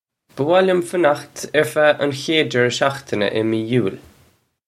Pronunciation for how to say
Buh wah lyum fanukht urr fa un khayd jerra shokhtina ih mee Yule
This is an approximate phonetic pronunciation of the phrase.